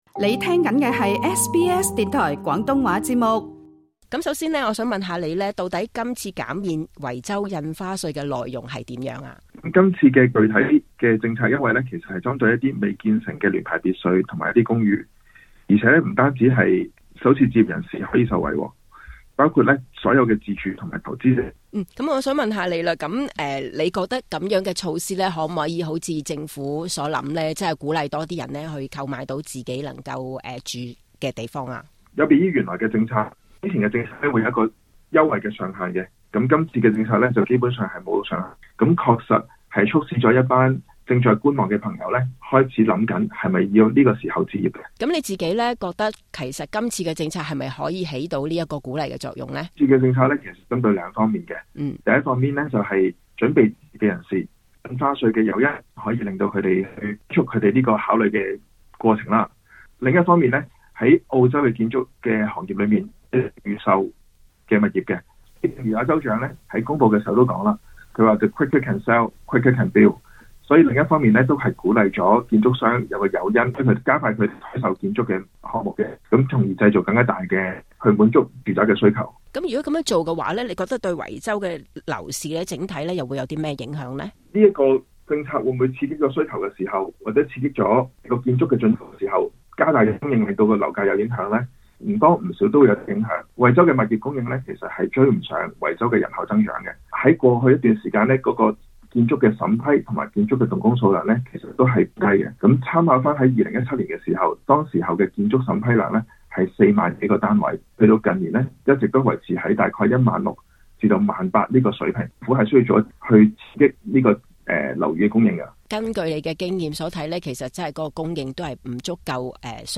【時事專訪】